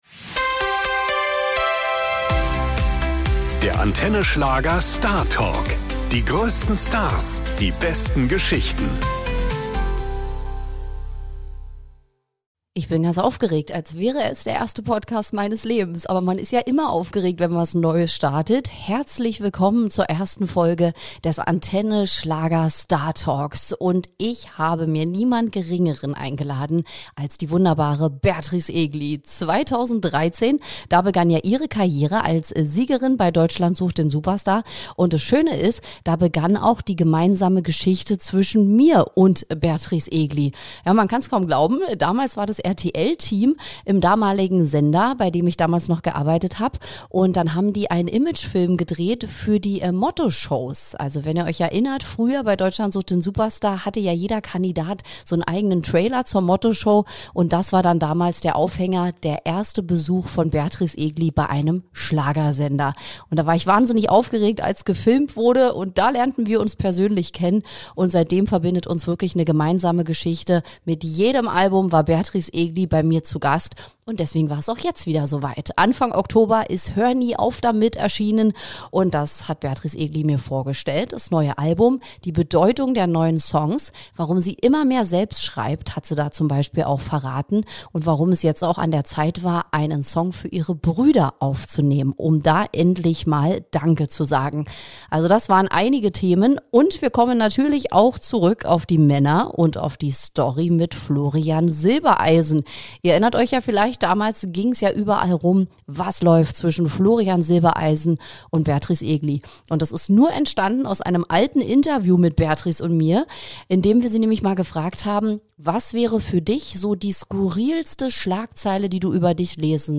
Beschreibung vor 5 Monaten Beatrice Egli im Studio - und sofort sprühen die echten Emotionen!